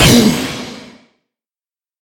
Minecraft Version Minecraft Version snapshot Latest Release | Latest Snapshot snapshot / assets / minecraft / sounds / mob / wither / hurt3.ogg Compare With Compare With Latest Release | Latest Snapshot
hurt3.ogg